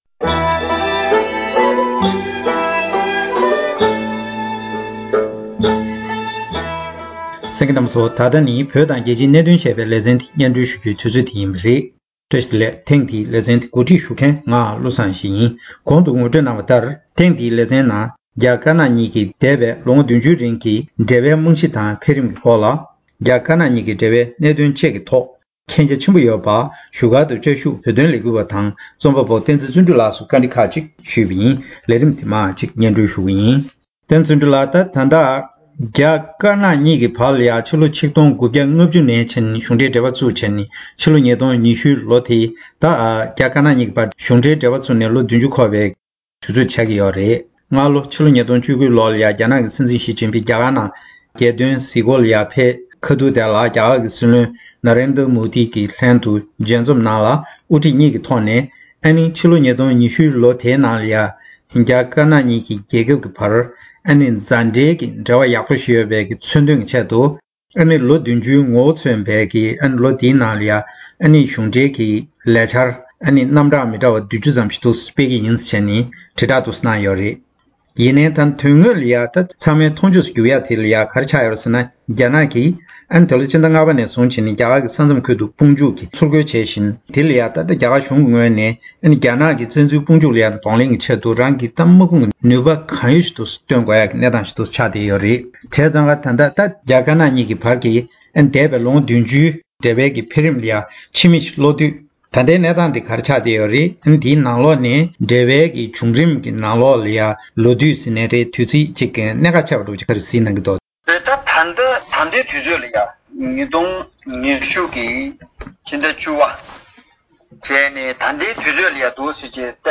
ཞལ་པར་ཐོག་བཀའ་དྲིས་ཞུས་པར་གསན་རོགས་ཞུ།།